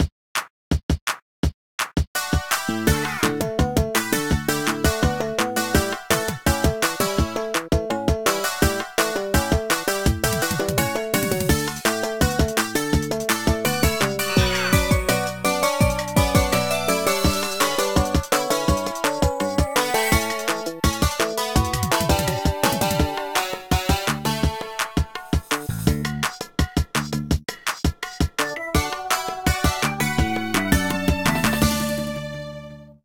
This file is an audio rip from a(n) Nintendo DS game.